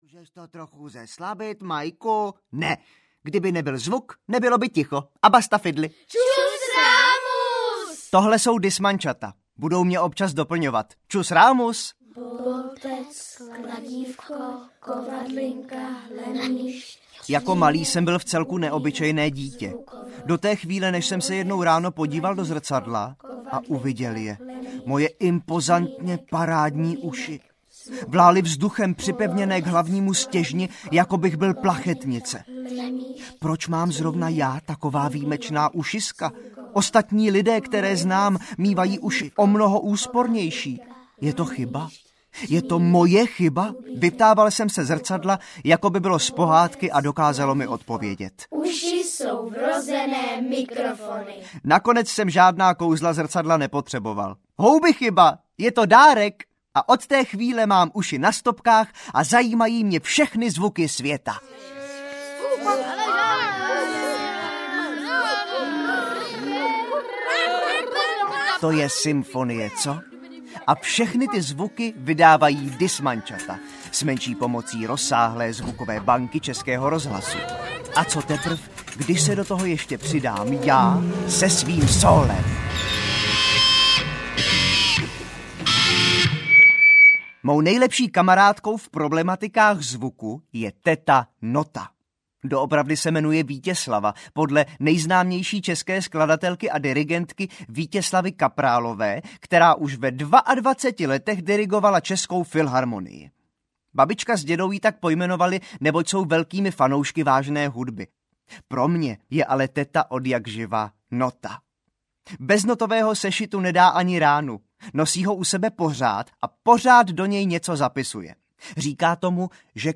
Rok ušatého Majka audiokniha
Ukázka z knihy